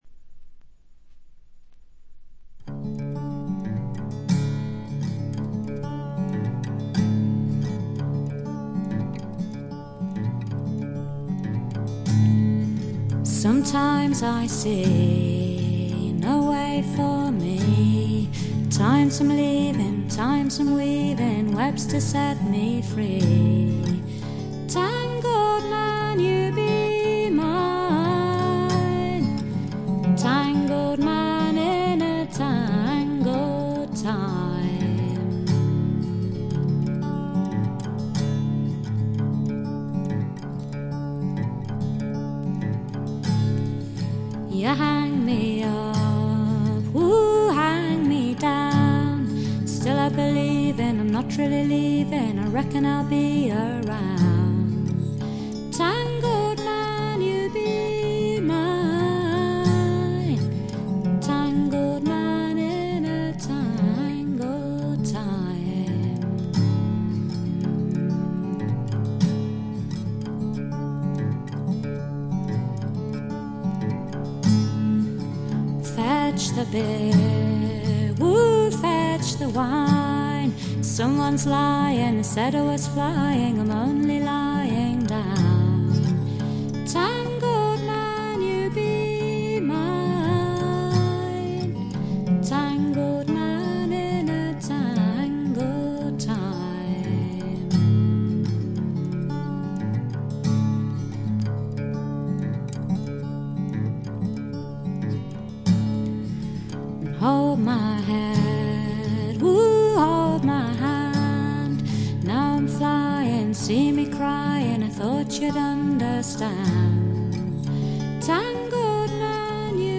演奏面は彼女自身が奏でるギター、ブズーキだけと非常にシンプルなもの。
アルバム全体はしっとりした雰囲気で、不思議な浮遊感があり少しくぐもったかわいい歌声が「夢の世界」を彷徨させてくれます。
試聴曲は現品からの取り込み音源です。